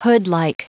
Prononciation du mot hoodlike en anglais (fichier audio)
Prononciation du mot : hoodlike